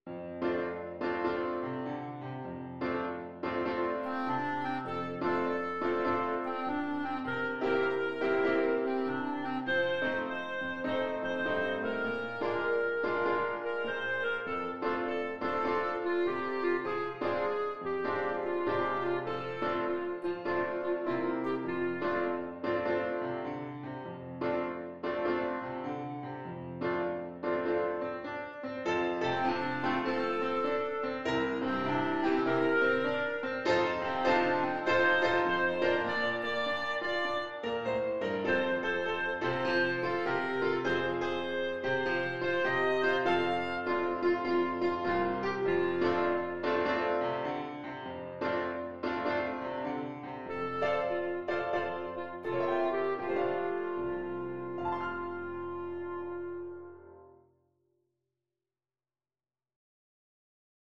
4/4 (View more 4/4 Music)
Moderate swing